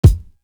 The Drama Kick.wav